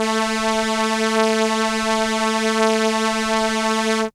/audio/sounds/Extra Packs/musicradar-synth-samples/ARP Solina/Violin/
Violin A3.WAV